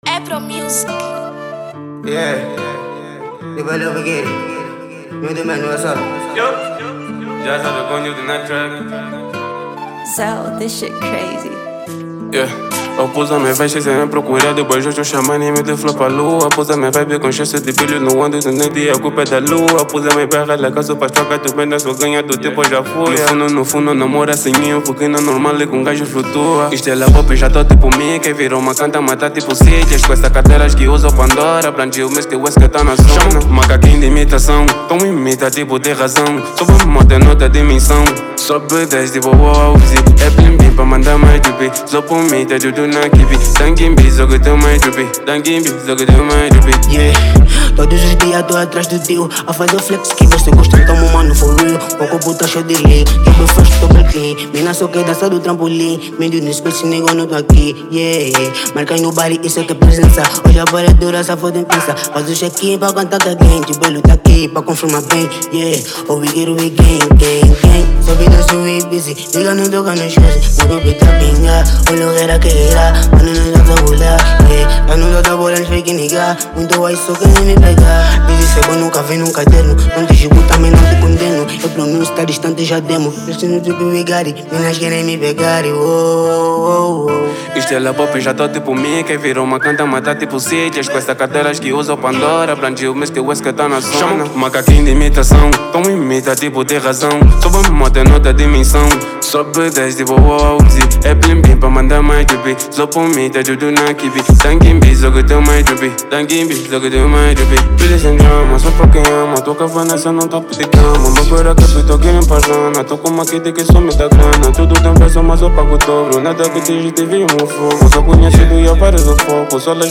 Género : Trap